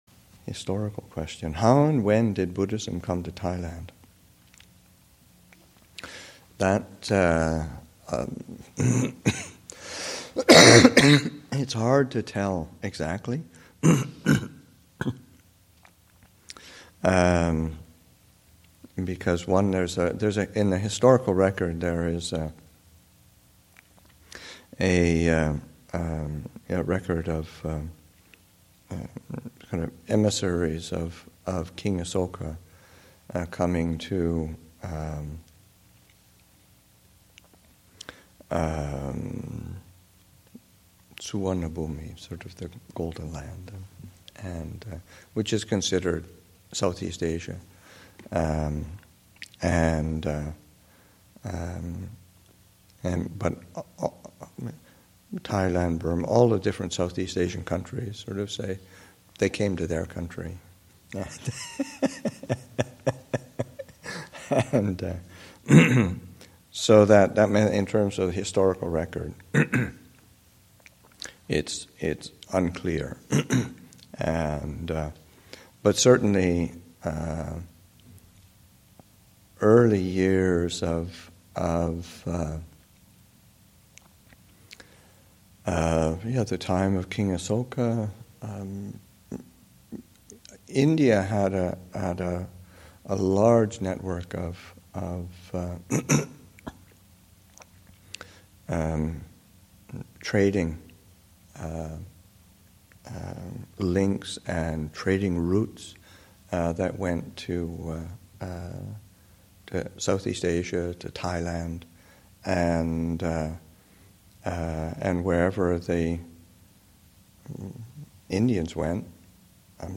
2014 Thanksgiving Monastic Retreat, Session 6 – Nov. 27, 2014